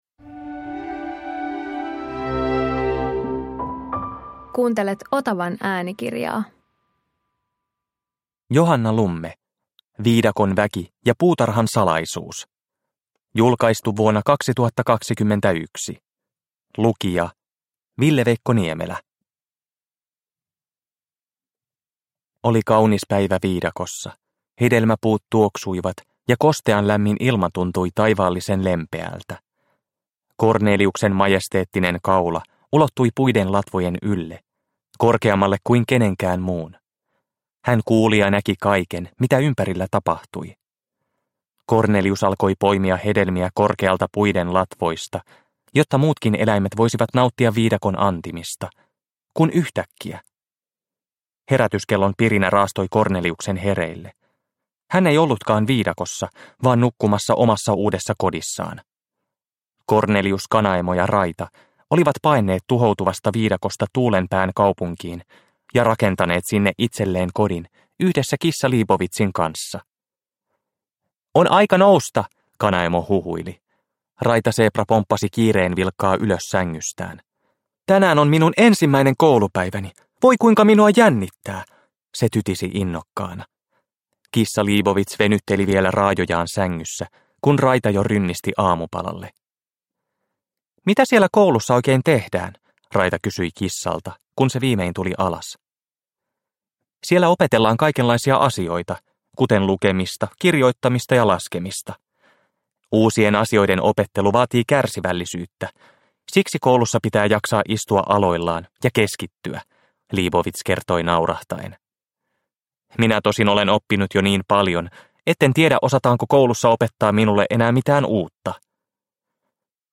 Viidakon väki ja puutarhan salaisuus – Ljudbok – Laddas ner
Uppläsare